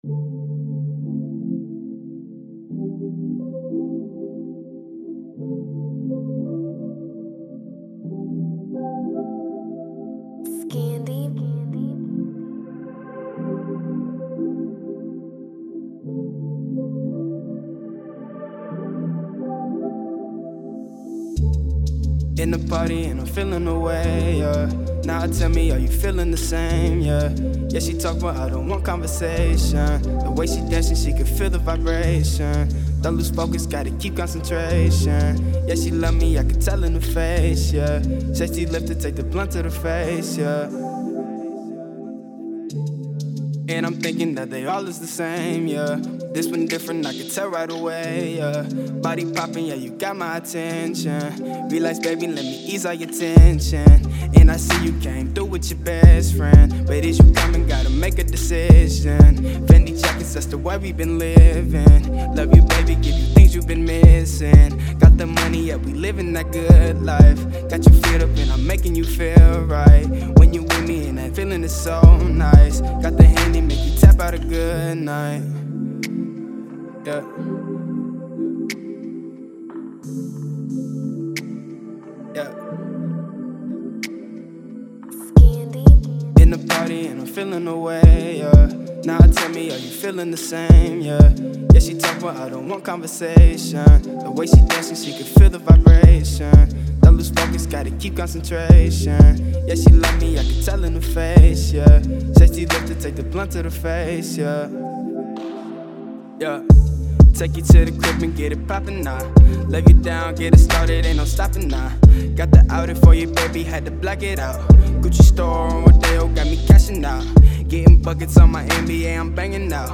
Description : Hip Hop with an R&B blend